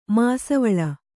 ♪ māsavaḷa